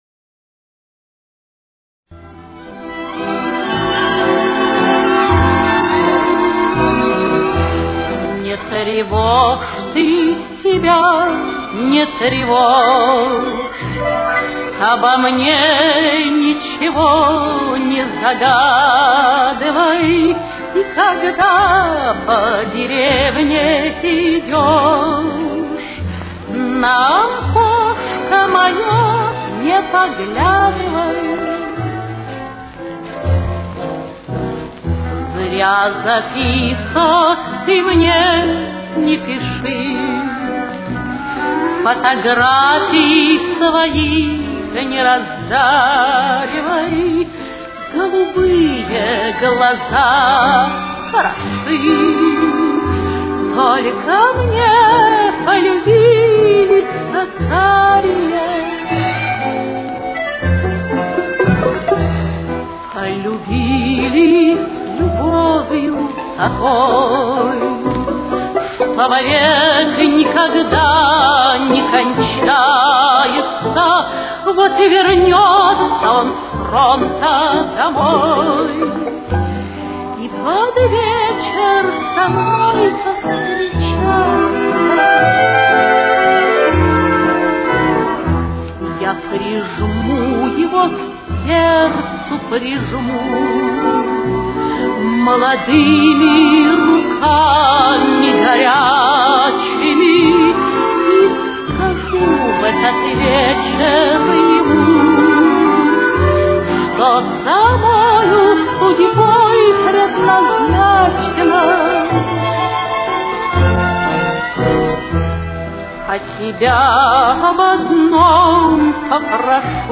Темп: 123.